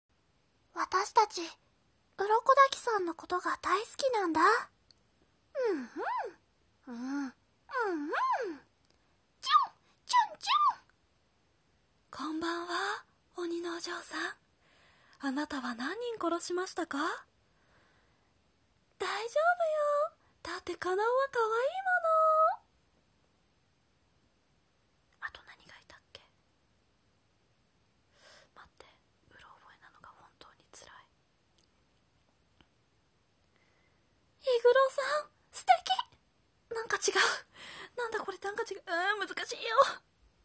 声真似練習